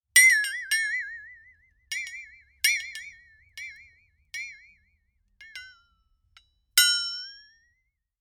Звуки ударов предметов